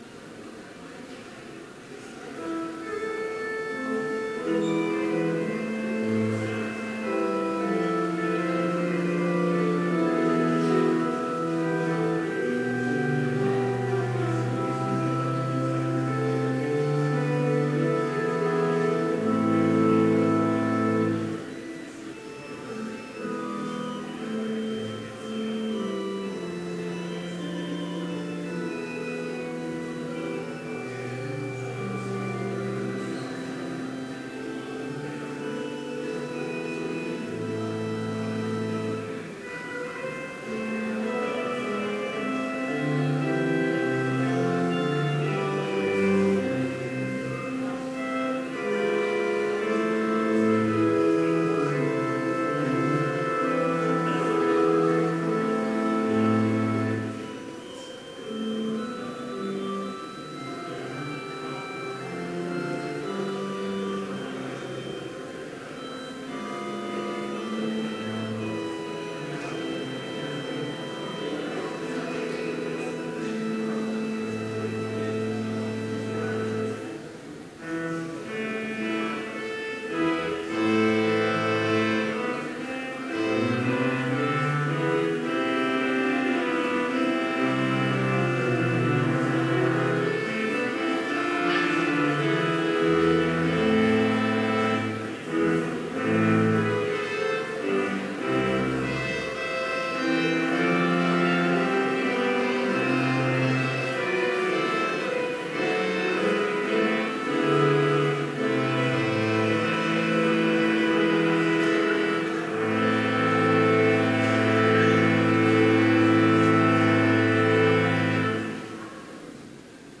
Complete service audio for Chapel - January 30, 2017